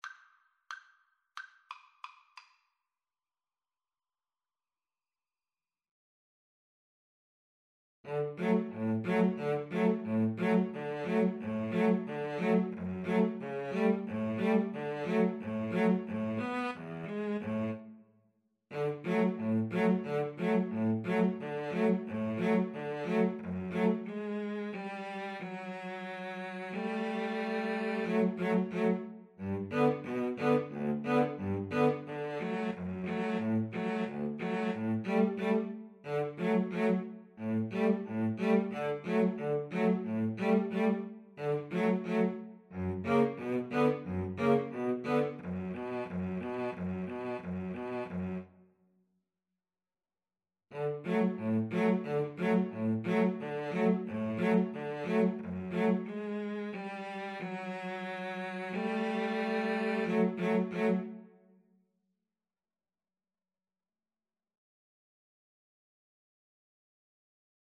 Allegro =180 (View more music marked Allegro)
Classical (View more Classical Violin-Cello Duet Music)